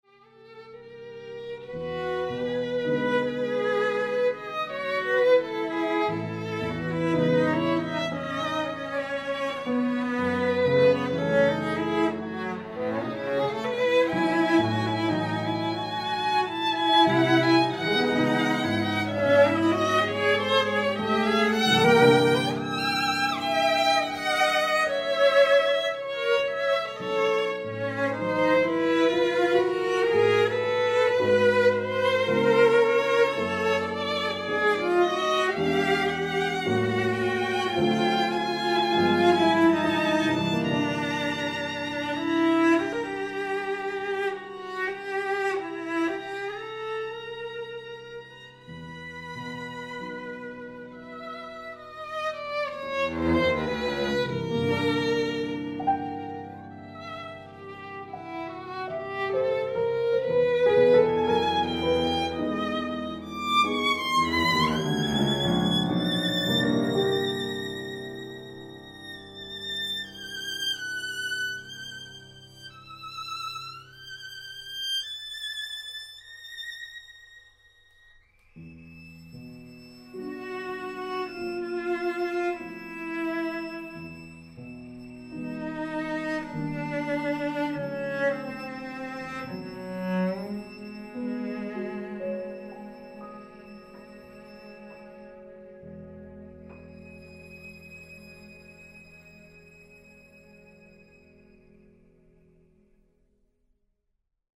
violin, violoncello, piano